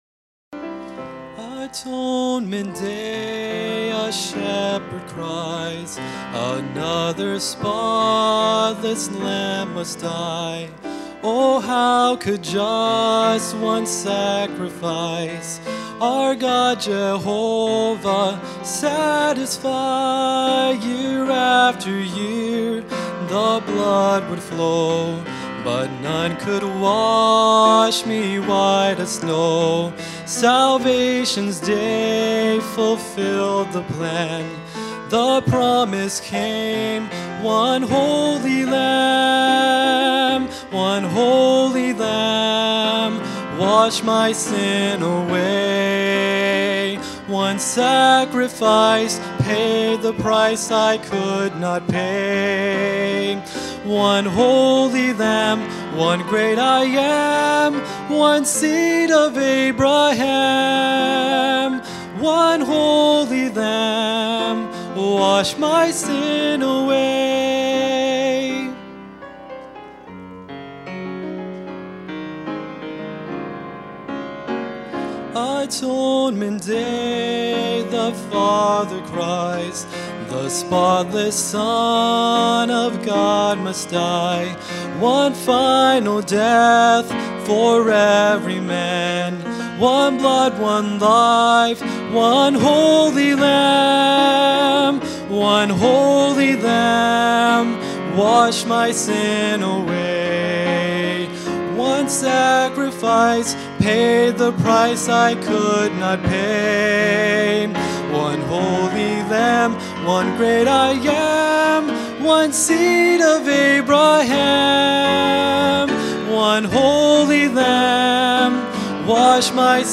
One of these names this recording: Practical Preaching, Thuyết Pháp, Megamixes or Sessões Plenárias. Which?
Practical Preaching